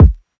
Kick (18).wav